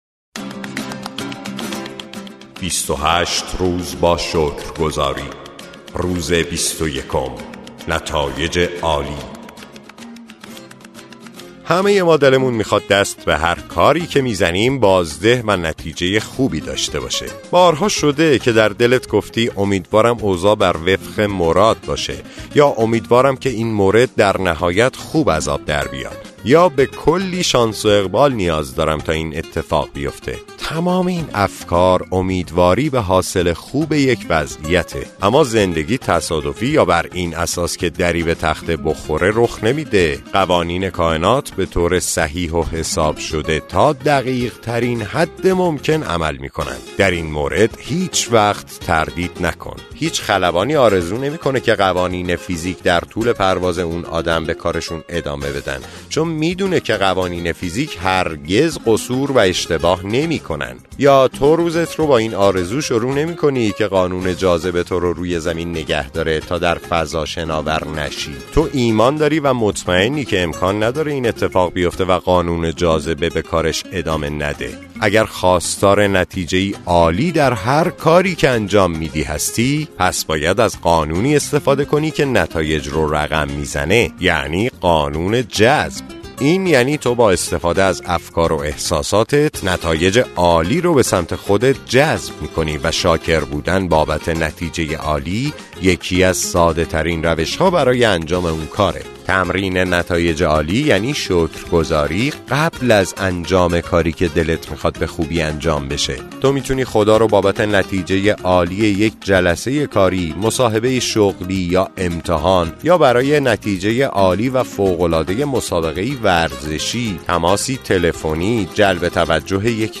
کتاب صوتی معجزه شکرگزاری